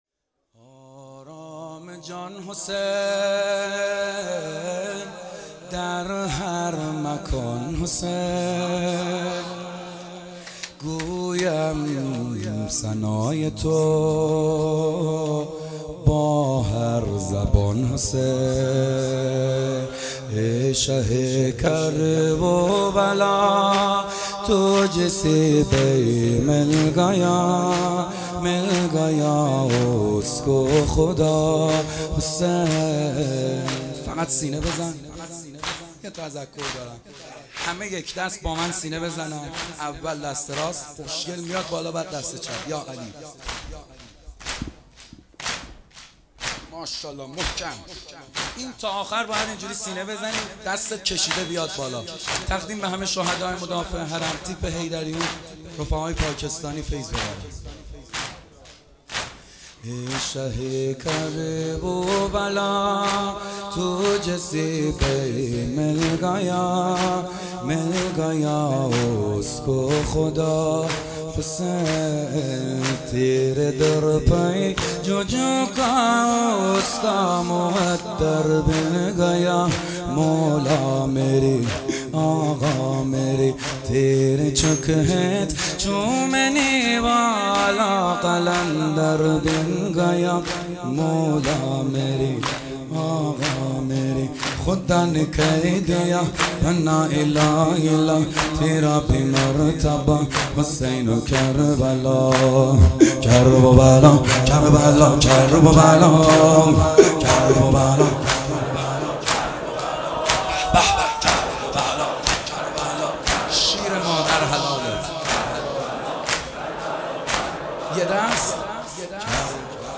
صوت/ مداحی چند زبانه محمدحسین حدادیان
محمد حسین حدادیان مداح اهل بیت (ع) نوحه‌ای به چهار زبان پاکستانی، عربی، انگلیسی و فارسی خواند و شهدای مدافه حرم تیپ حیدریون تقدیم کرد.